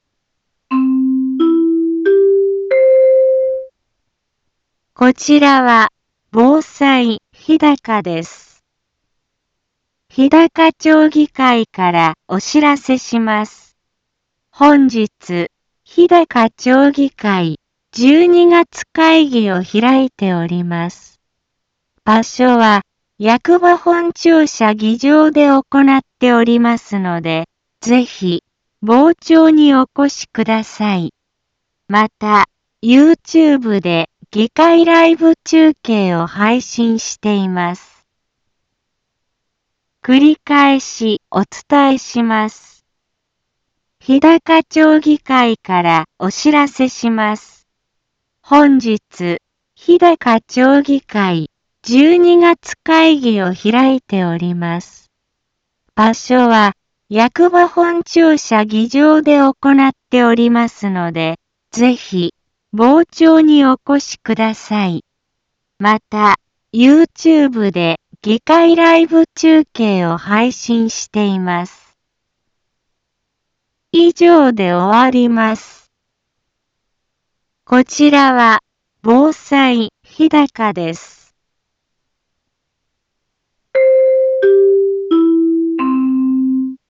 BO-SAI navi Back Home 一般放送情報 音声放送 再生 一般放送情報 登録日時：2023-12-13 10:03:05 タイトル：日高町議会12月会議のお知らせ インフォメーション： 日高町議会からお知らせします。 本日、日高町議会12月会議を開いております。